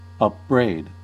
Ääntäminen
US : IPA : [ʌp.ˈbɹeɪd]